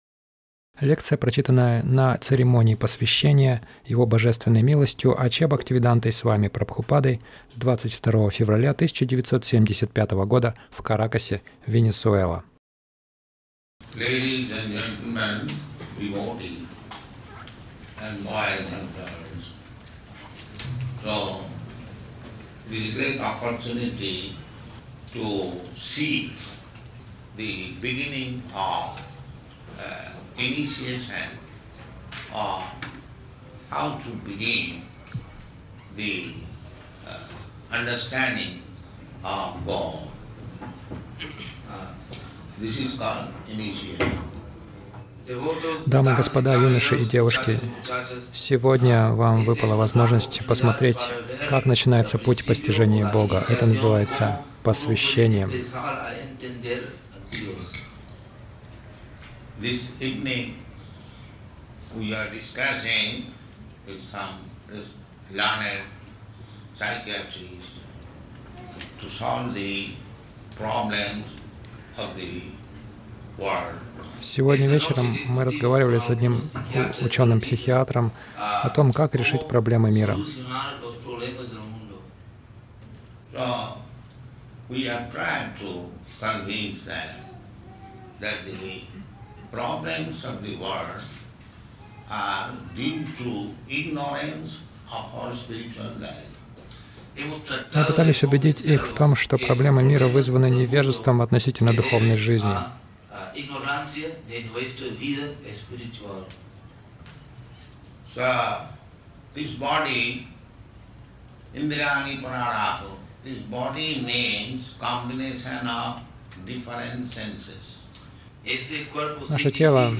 Лекция на инициации — Шанс полюбить Бога
Милость Прабхупады Аудиолекции и книги 22.02.1975 Лекции | Каракас Лекция на инициации — Шанс полюбить Бога Загрузка...